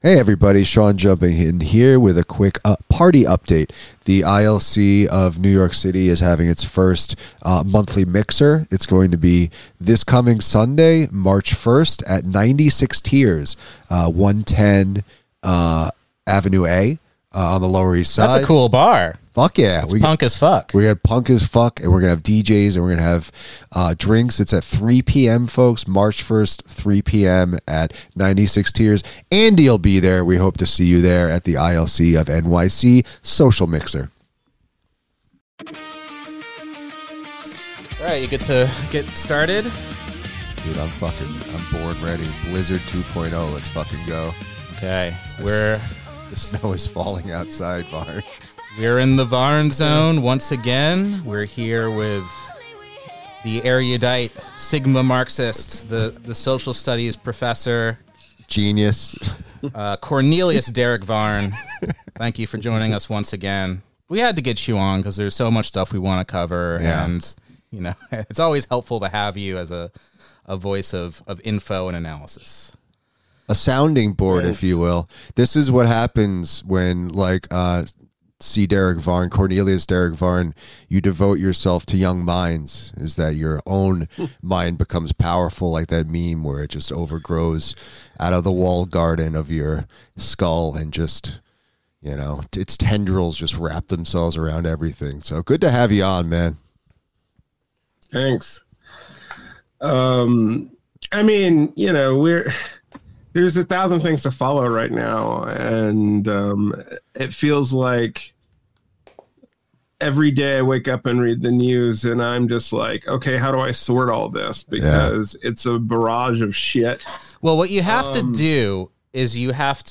News ep!